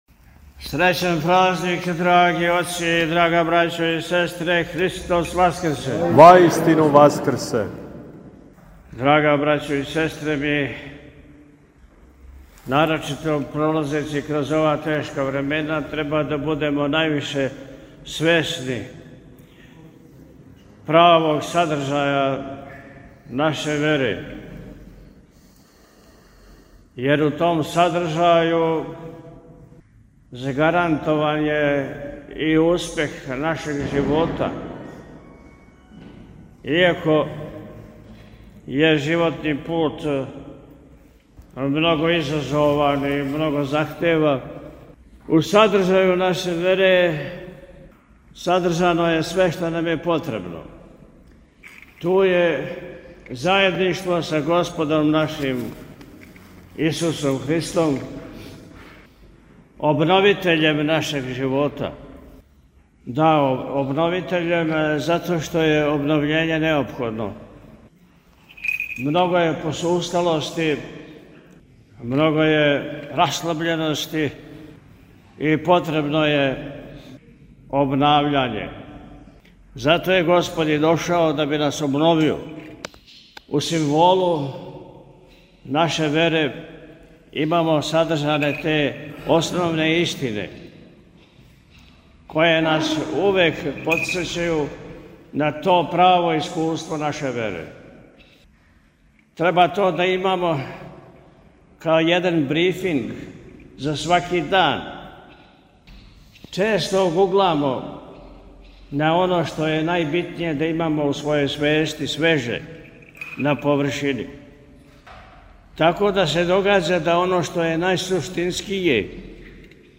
На Васкрсни уторак, 22. априла 2025. године, Његово Високопреосвештенство Архиепископ и Митрополит милешевски г. Атанасије служио је Божанску Литургију у храму Васкрсења Христовог у Прибоју.
Beseda-u-hramu-PB.mp3